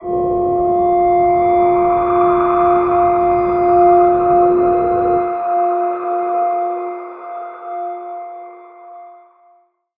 G_Crystal-F5-pp.wav